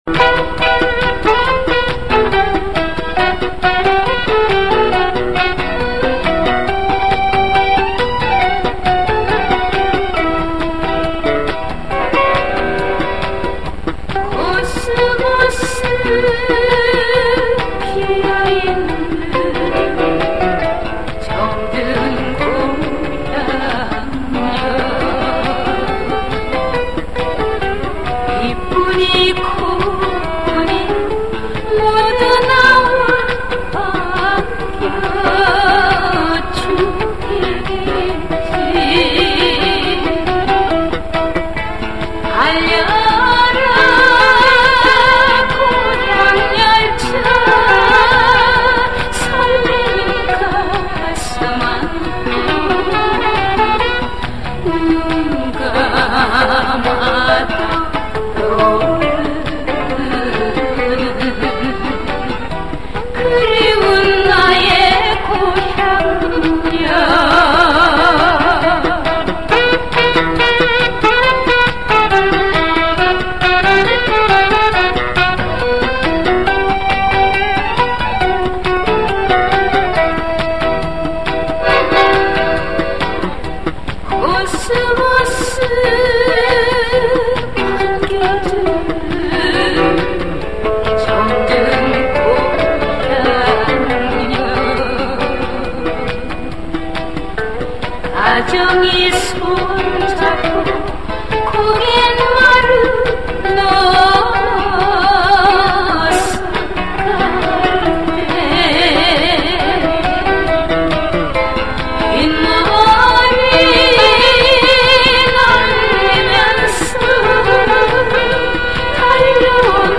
음반을 경매로 구입 복각 하였습니다.